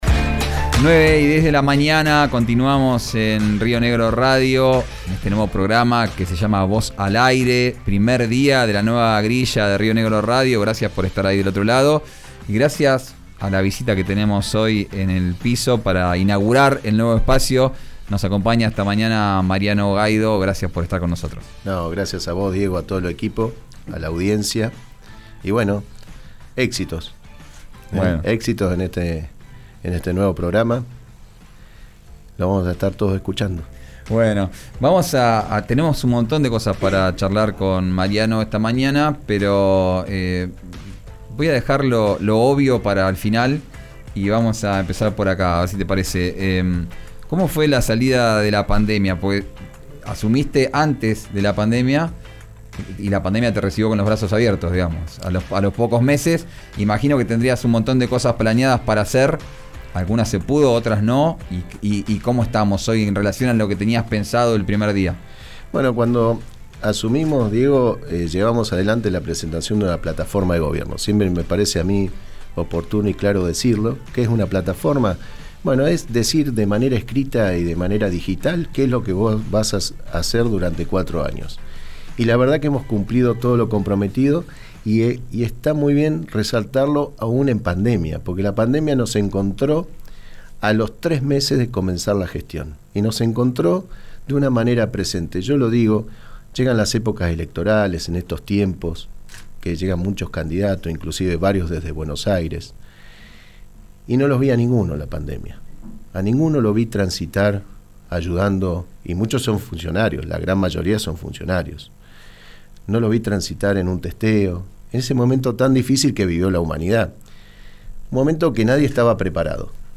El intendente de Neuquén, Mariano Gaido, visitó el estudio de RÍO NEGRO RADIO en el primer programa de 'Vos al Aire'. Escuchá la entrevista completa.